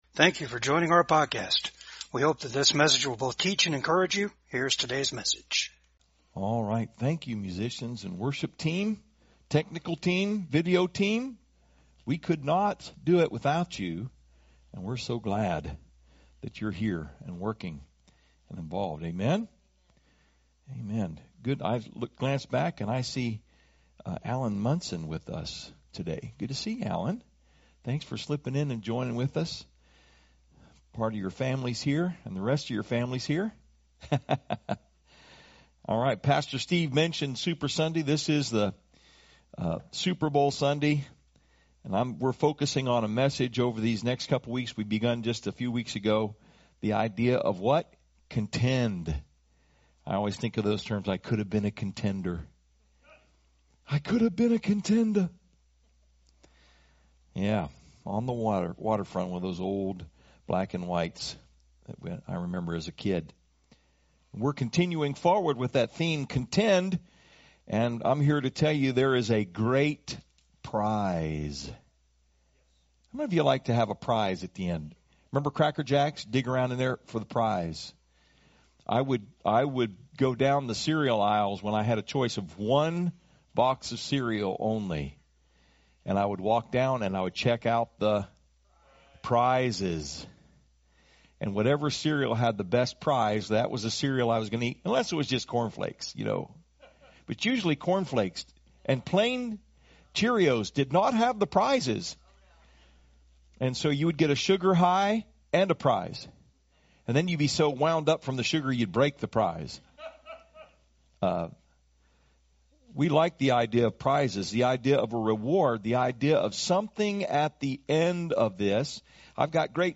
Philippians 3:10-14 Service Type: VCAG SUNDAY SERVICE TO REALLY KNOW CHRIST TO REALLY GET A GRIP ON GRACE!